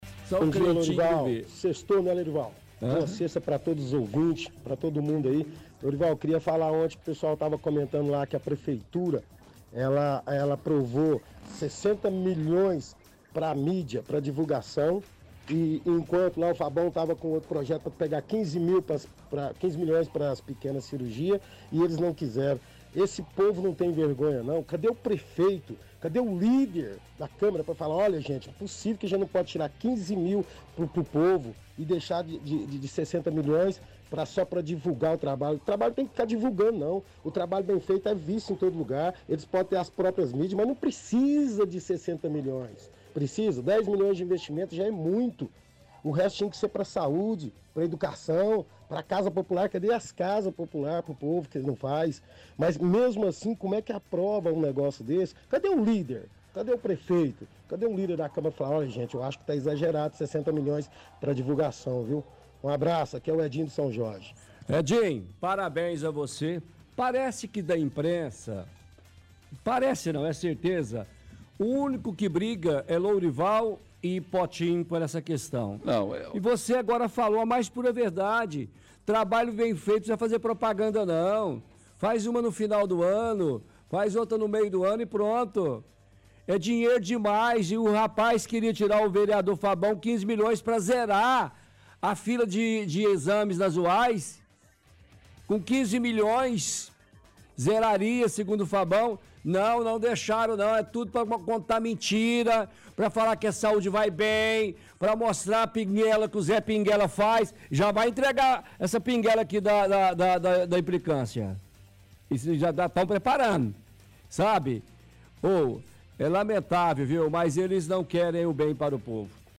– Ouvinte reclama de vereadores não terem aprovado projeto do vereador Fabão, que destinava R$ 15 milhões de verba de publicidade para outras finalidades.